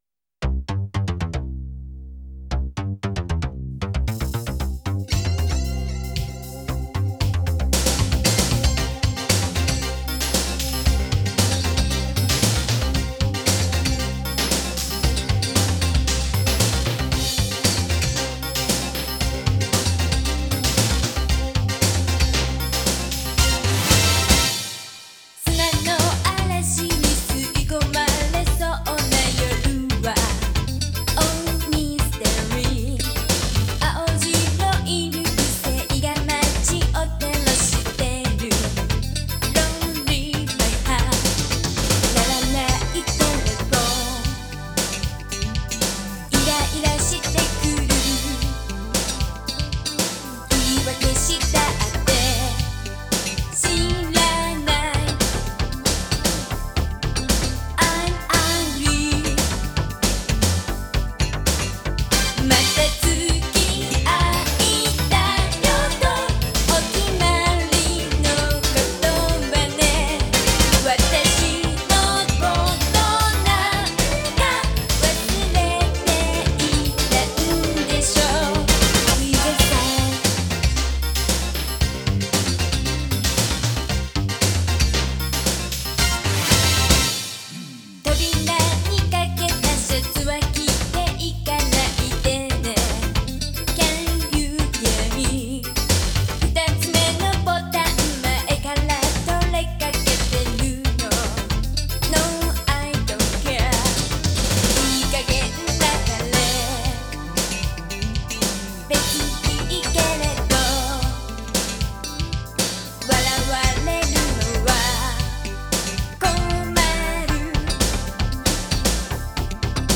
ジャンル(スタイル) JAPANESE POP / R&B